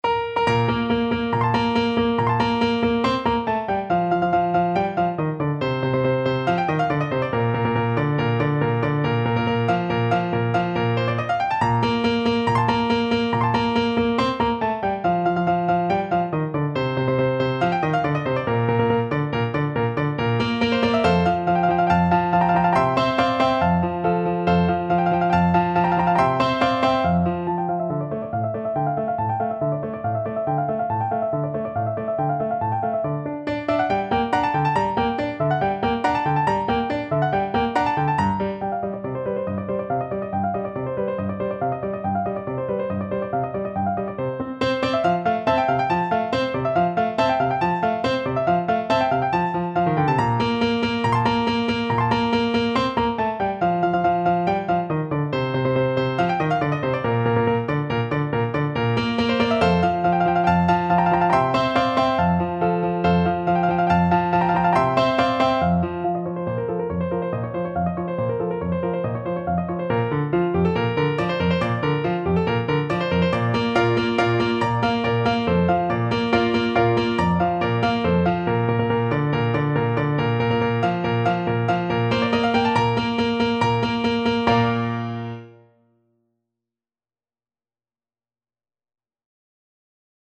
Bb major (Sounding Pitch) F major (French Horn in F) (View more Bb major Music for French Horn )
2/4 (View more 2/4 Music)
~ = 140 Allegro vivace (View more music marked Allegro)
Classical (View more Classical French Horn Music)